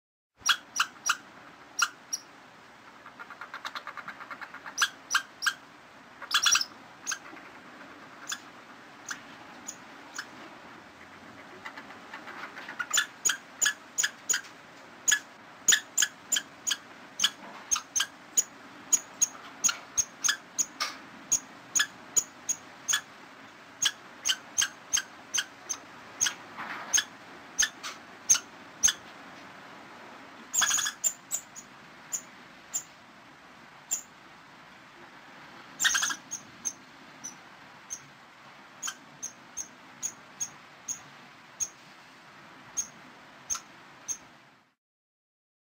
دانلود صدای سنجاب از ساعد نیوز با لینک مستقیم و کیفیت بالا
جلوه های صوتی